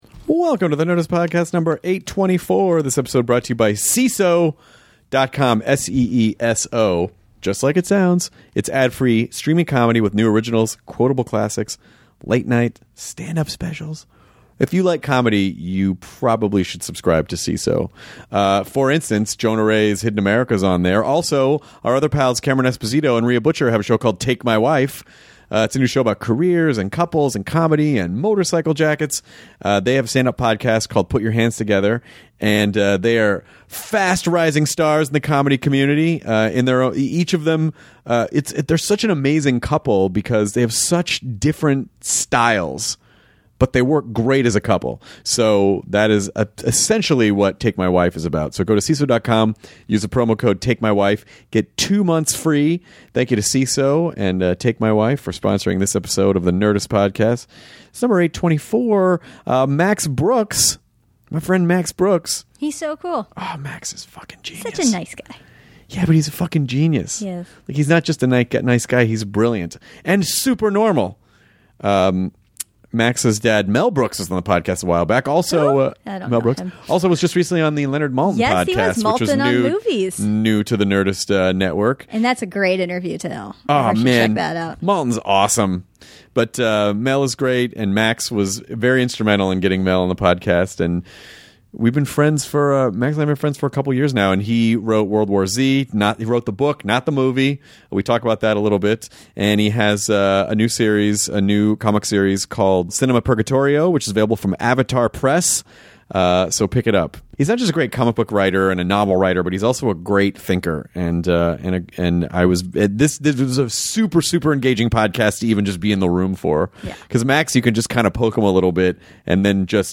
They then get into a deep conversation about world politics, how science fiction used to include more social issues, and how Americans have changed the way they cope with tragedy. He then talks about his life as Mel Brooks’ son and his new graphic novel Cinema Purgatorio!